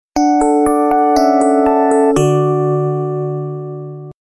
Стандартная мелодия SMS на телефон Blackberry